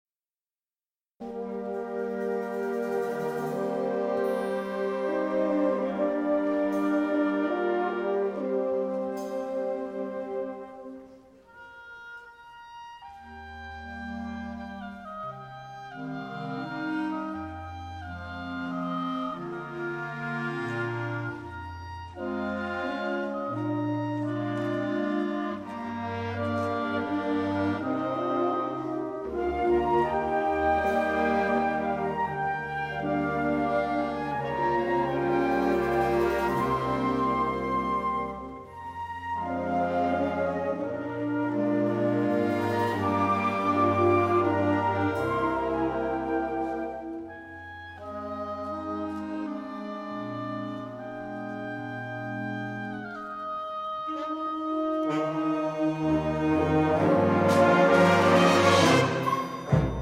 Wind Symphony
With: Wind Symphony